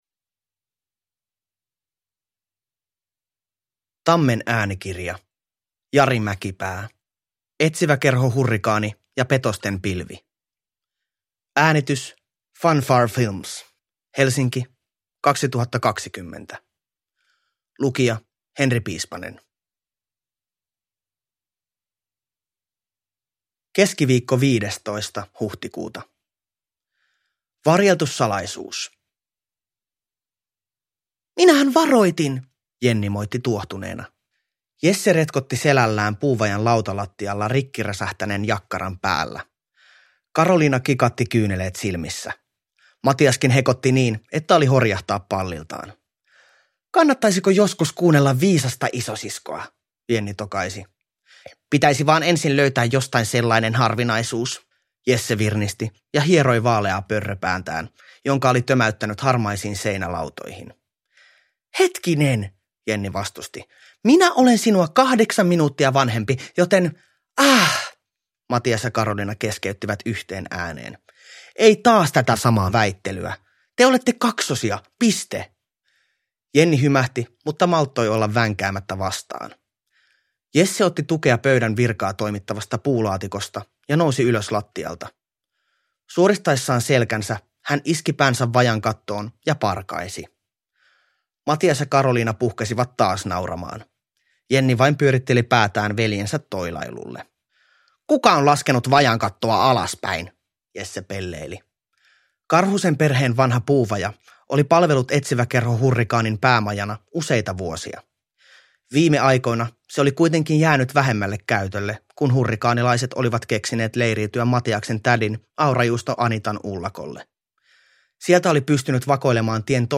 Etsiväkerho Hurrikaani ja petosten pilvi – Ljudbok – Laddas ner